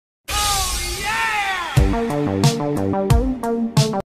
youtube-twitch-follow-sound-effect-1.mp3